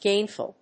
音節gain・ful 発音記号・読み方
/géɪnf(ə)l(米国英語), ˈgeɪnfʌl(英国英語)/